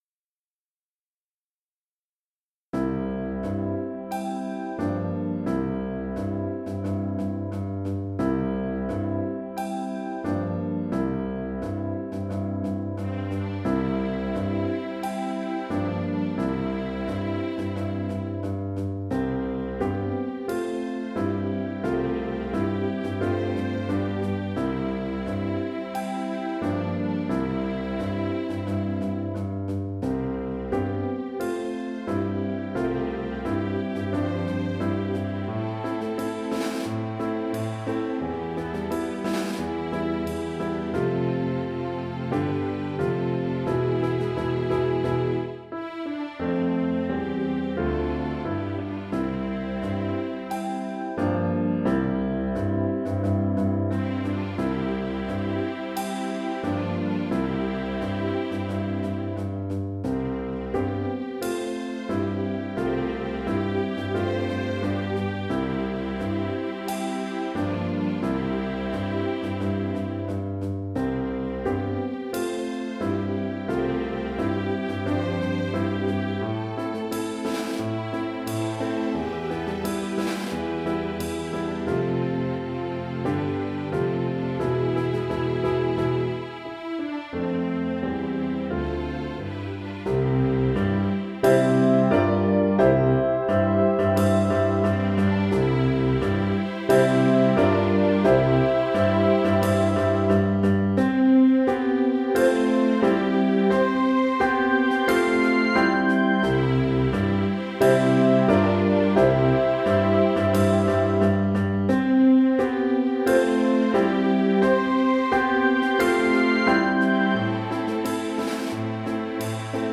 Sheet Music - Vocals Sheet Music Do-You-Hear-What-I-Hear.pdf ALL audio Sop I audio Sop II audio Alto audio VA do-you-hear-what-i-hear-VA.mp3 rehearsal recording rehearsal recording rehearsal recording YouTube